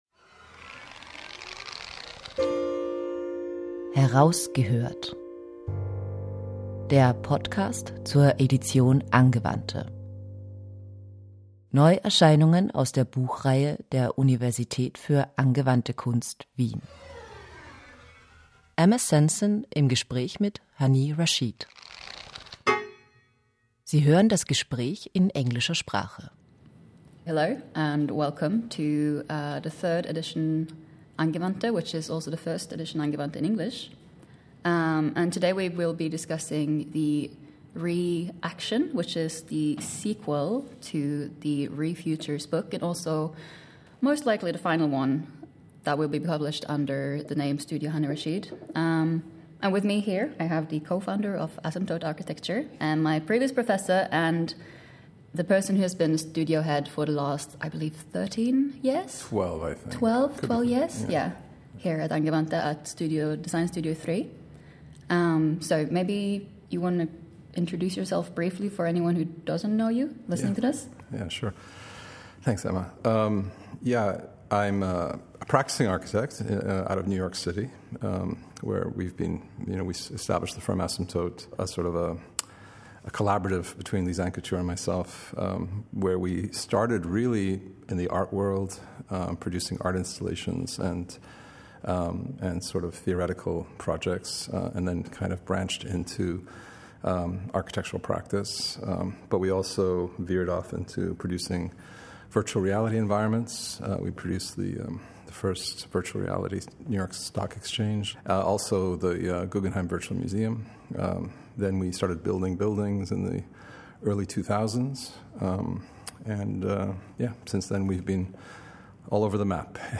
Heraus gehört: Der Podcast zur Edition Angewandte Hani Rashid in conversation